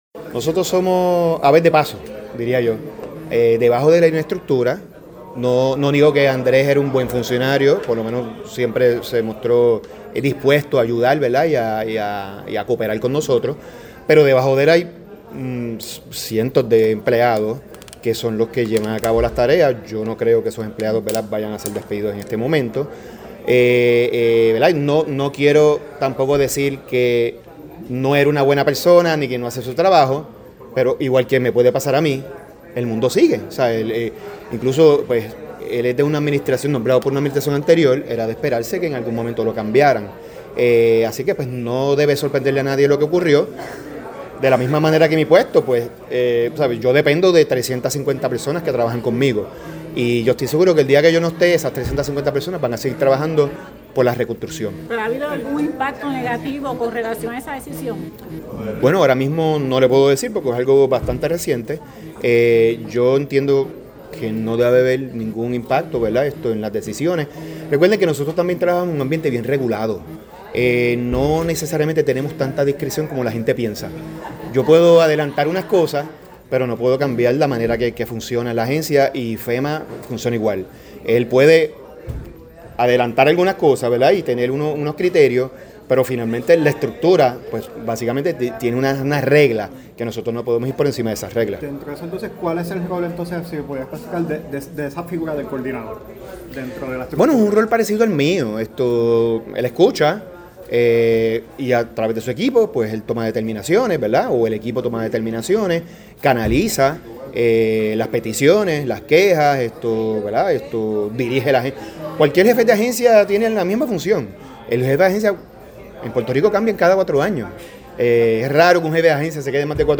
(24 de febrero de 2026)-Luego que el coordinador federal alterno para la recuperación en la Agencia Federal para el Manejo de Emergencias (FEMA, en inglés), Andrés García Martinó, fuera despedido de su puesto, el Lcdo. Eduardo Soria, director ejecutivo de COR3 a su llegada a la reunión de la Federación de Alcaldes; manifestó que Andrés era un buen funcionario, por lo menos siempre se mostró dispuesto a ayudar y a cooperar con su agencia.